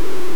poison_effect.ogg